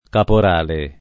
caporale [ kapor # le ] s. m.